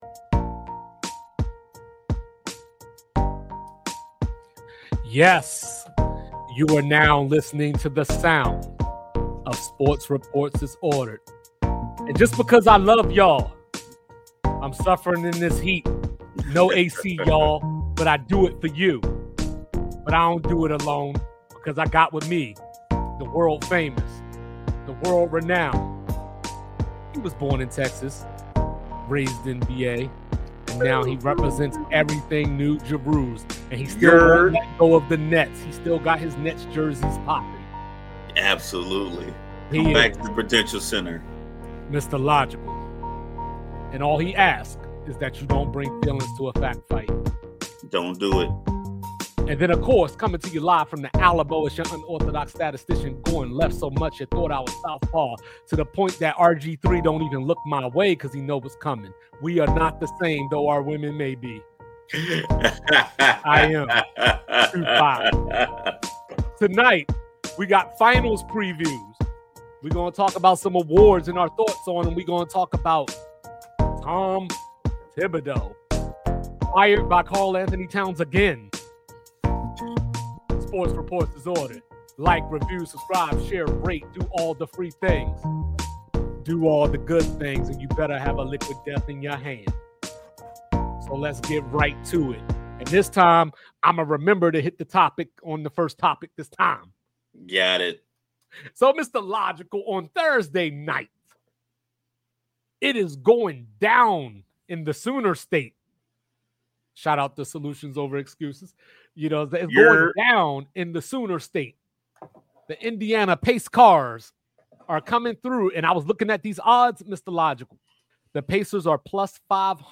Two Vets come together to talk about their passion.